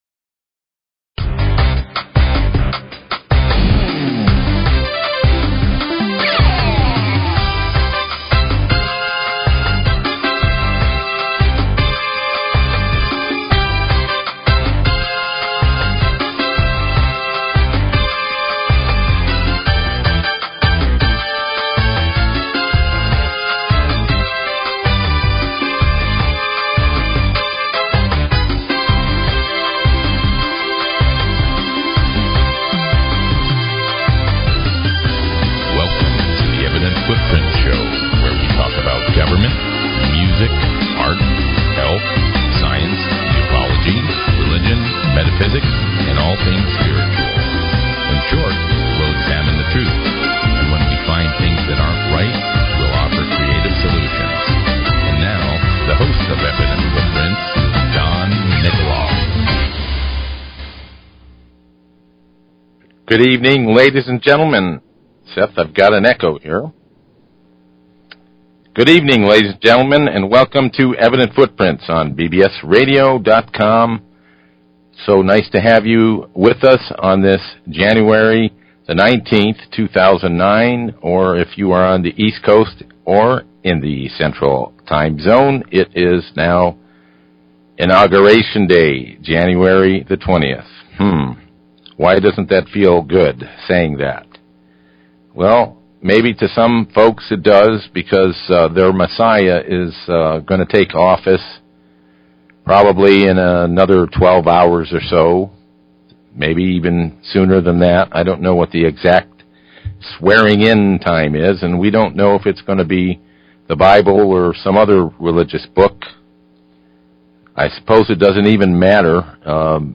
Environmental Scientist, Health Consultant, Herbalist